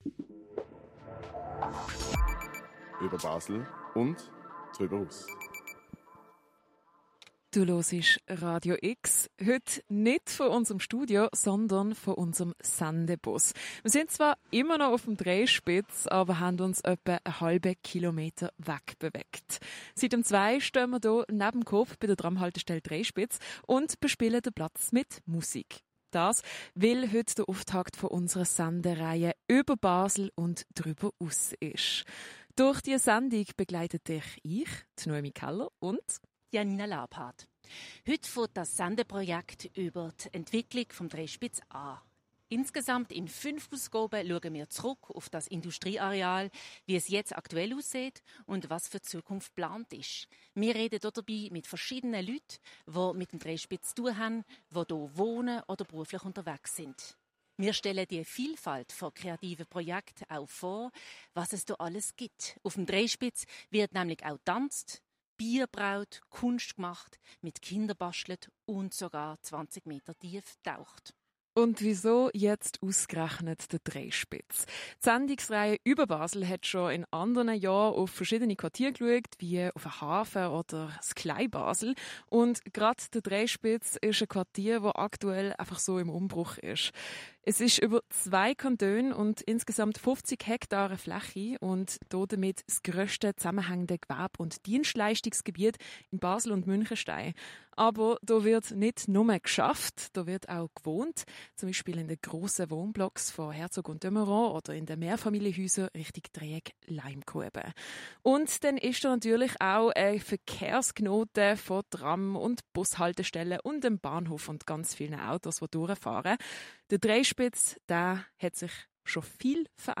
Livesendung vom Dreispitz 1. Folge ~ Spezialthemen Podcast